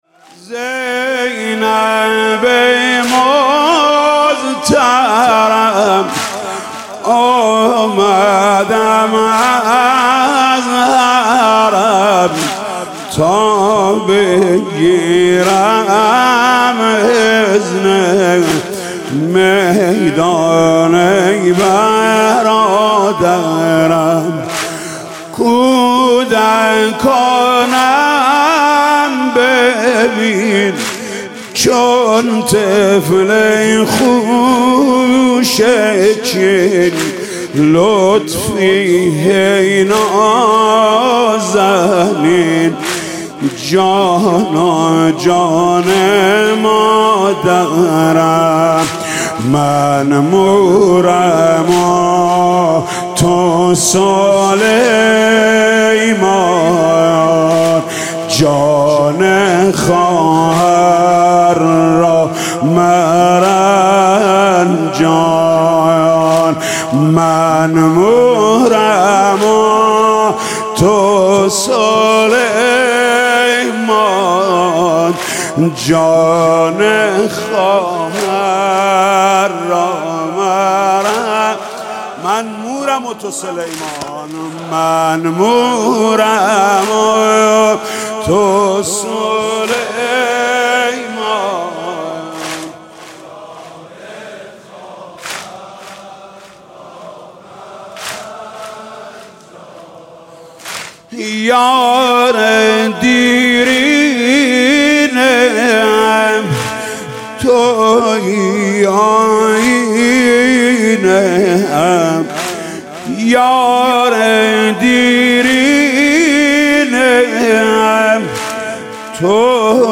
گلچین مداحی شب چهارم ماه محرم 1403 | ضیاءالصالحین